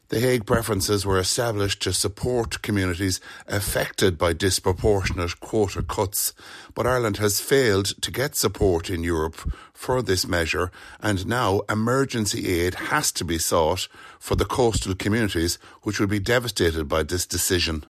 Midlands North West MEP Ciaran Mullooly says the news is devastating for these people: